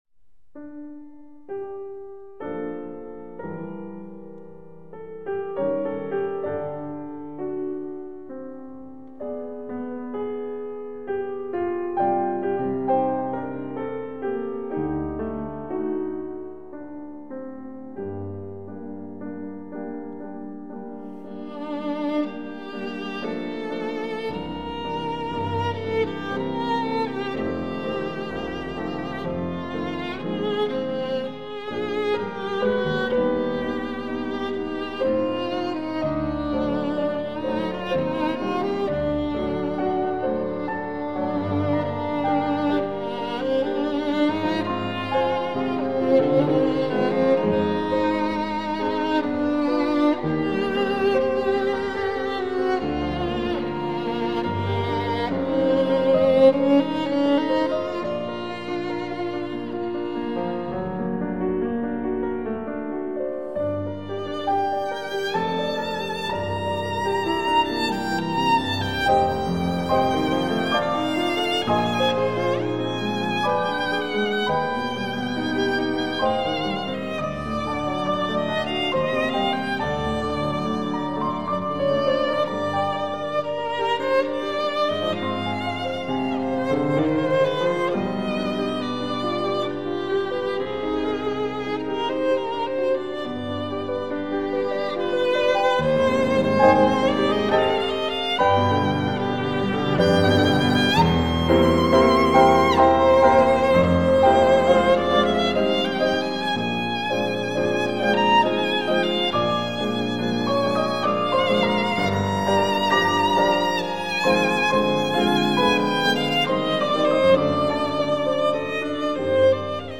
★ 著名工程師採單點錄音，高傳真效果完美呈現！
★ 令人顫抖著迷的小提琴美音之最，發燒必備珍品！